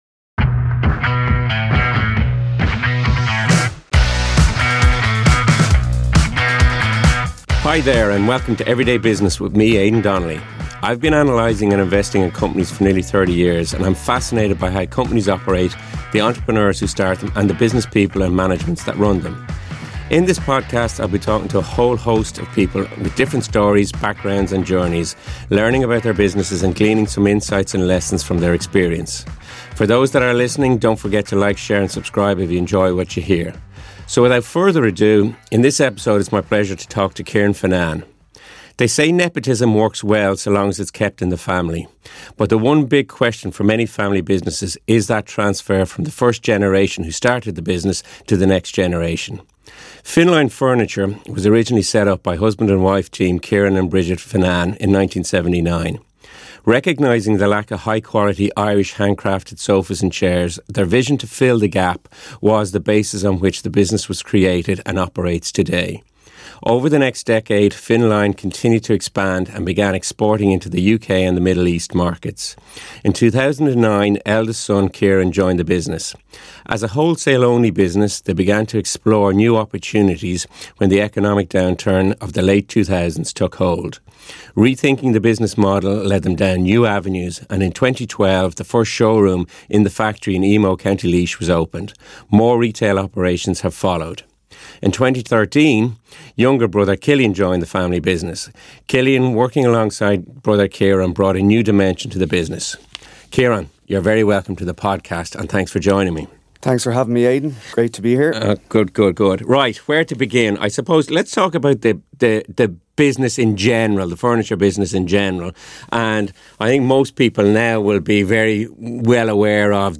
This podcast brings you insightful conversation